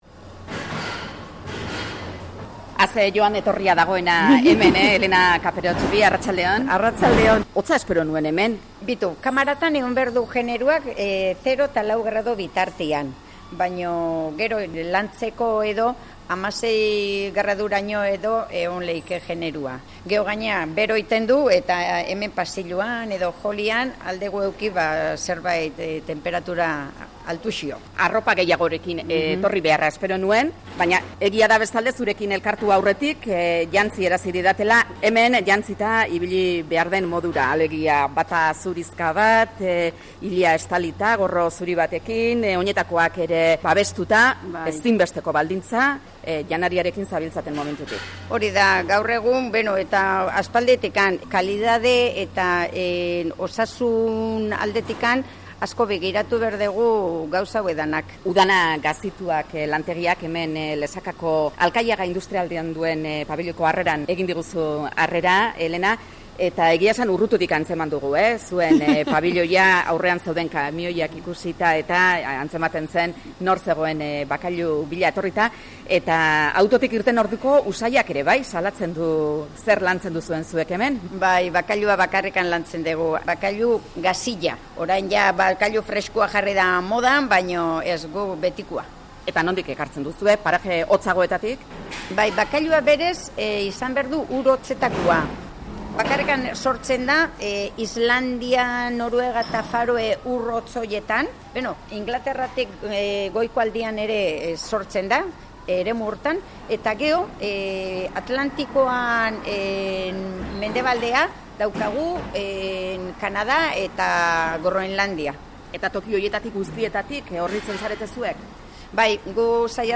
Audioa: Lesakako UDANA gazituak lantegian izan gara Euskadi Irratiko Baipasan